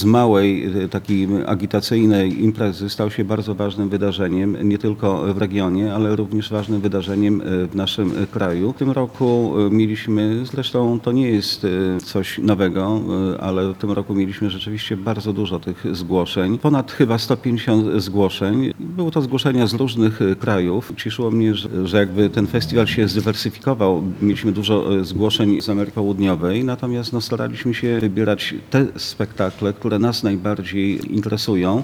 konfa-waliza-2.mp3